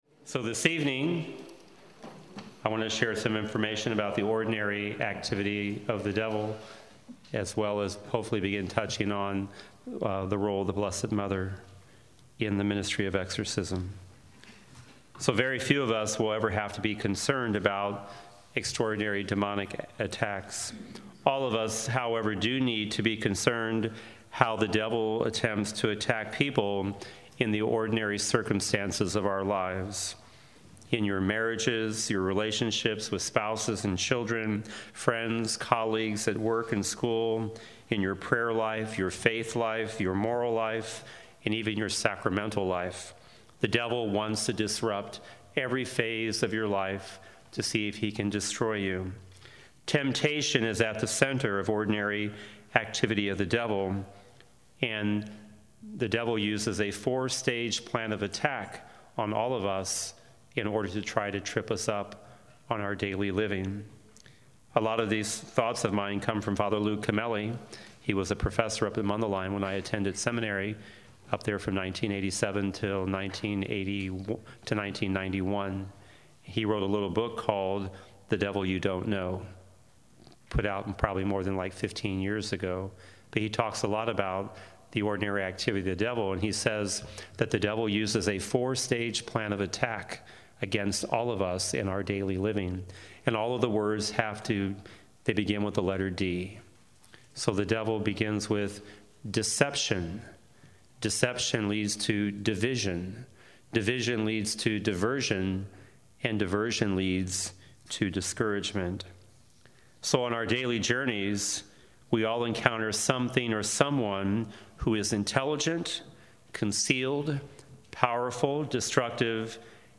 fourth talk of five at our conference on exorcism in January 2020